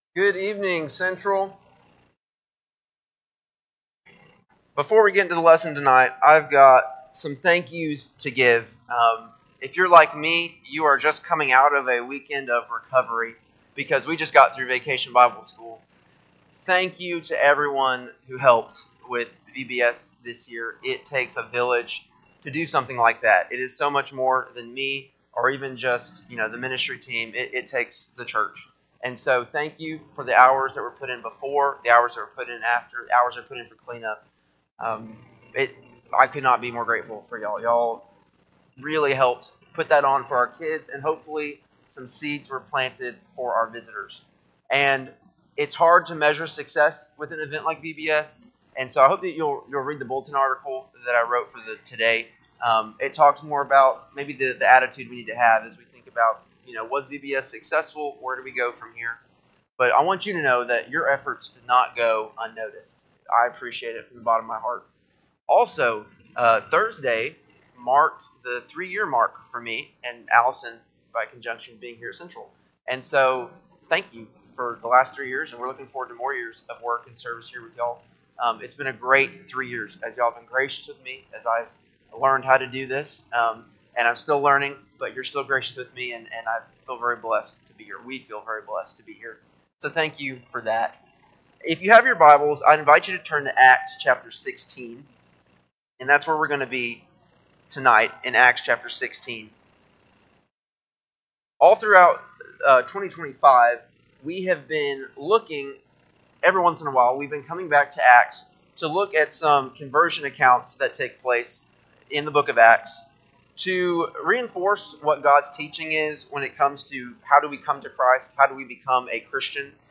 6-8-25-Sunday-PM-Sermon.mp3